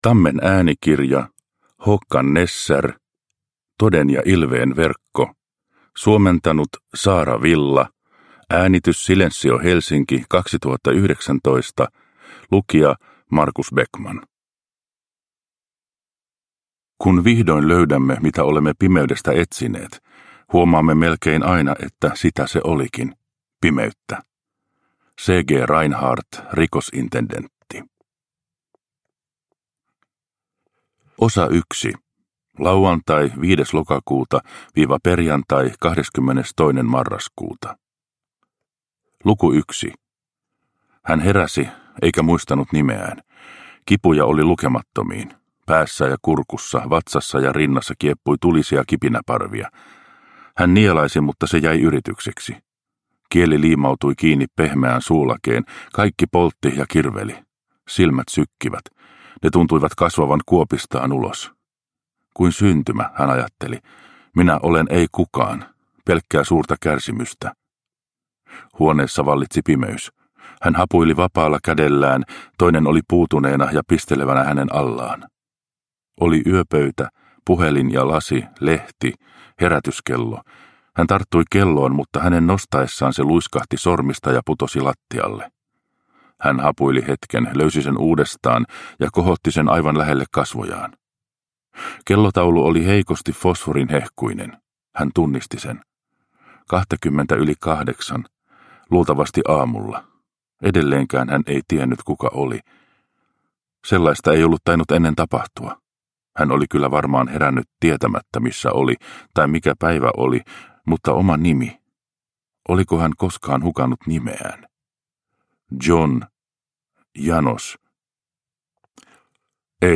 Toden ja ilveen verkko – Ljudbok – Laddas ner